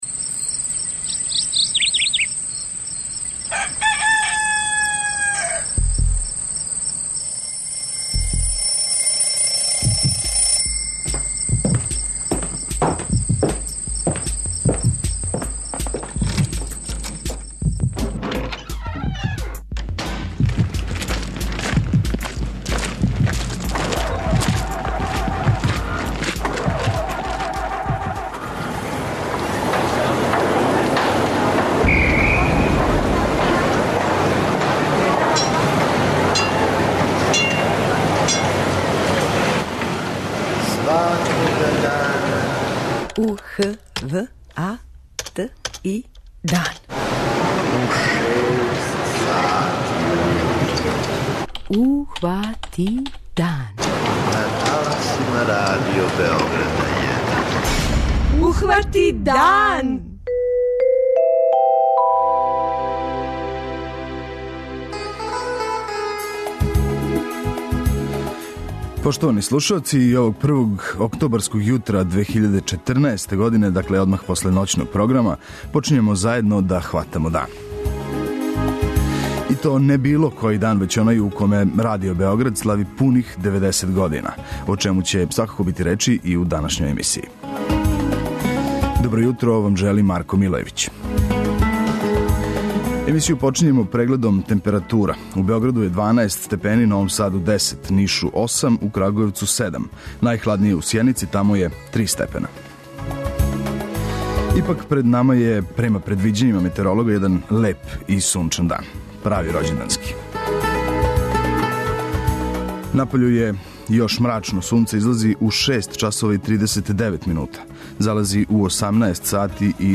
преузми : 85.89 MB Ухвати дан Autor: Група аутора Јутарњи програм Радио Београда 1!